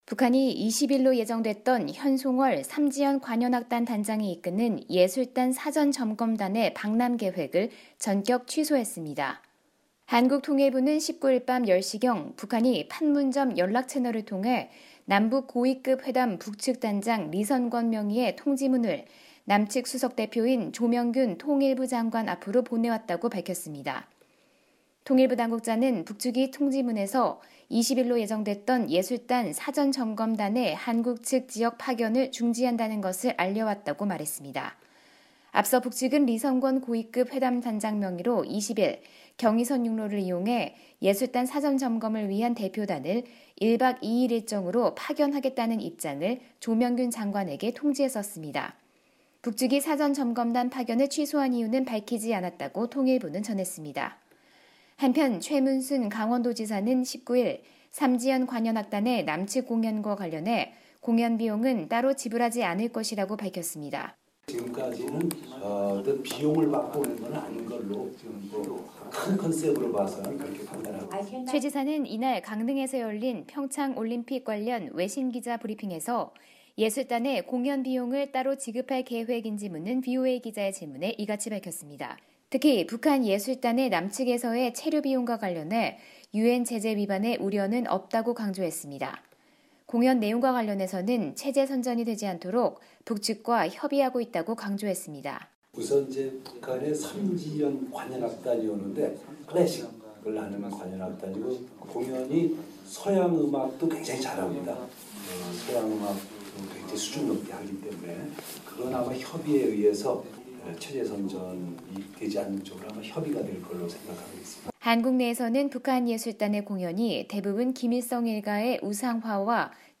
[특파원 리포트 오디오] 북한, 현송월 등 예술단 사전점검단 한국 방문 전격 취소